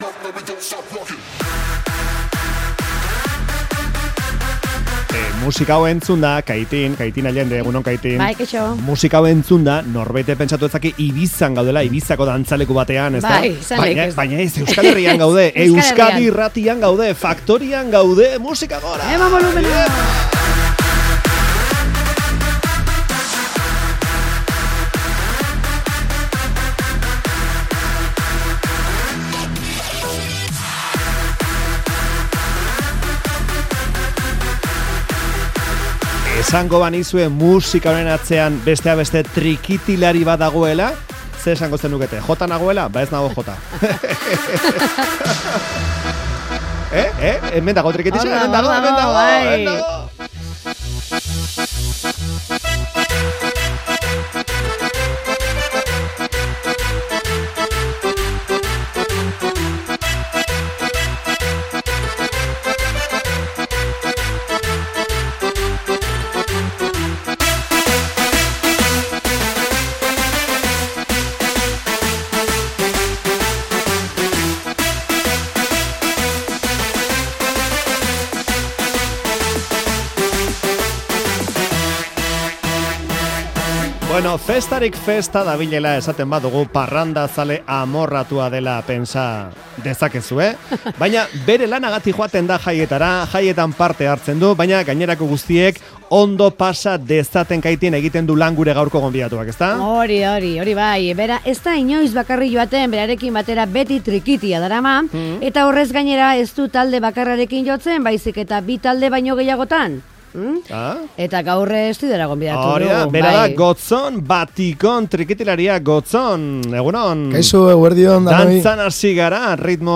berriketan